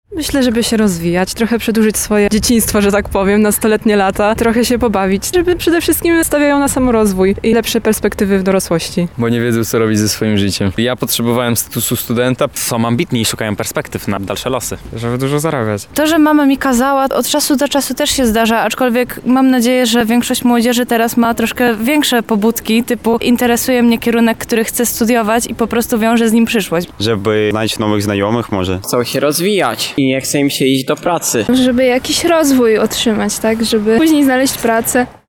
O tym, dlaczego warto się uczyć mówią studenci:
Sonda